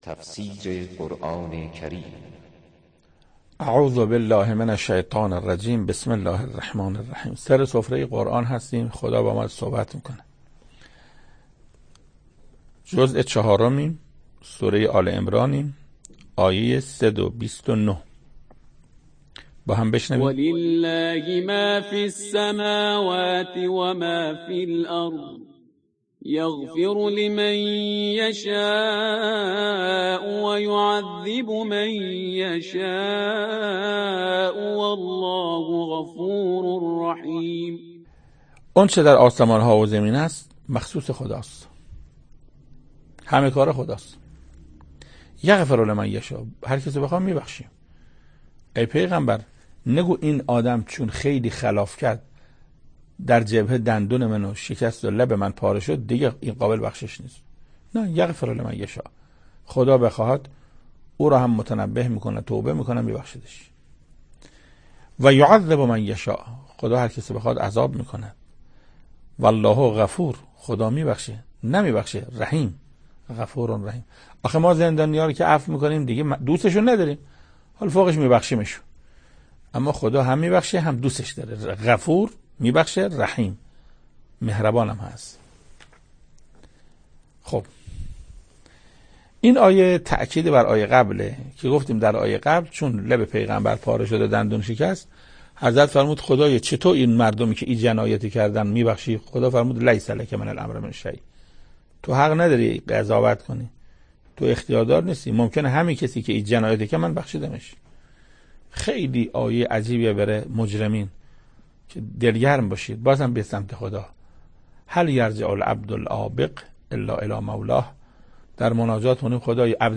تفسیر صد و بیست و نهمین آیه از سوره مبارکه آل عمران توسط حجت الاسلام استاد محسن قرائتی به مدت 9 دقیقه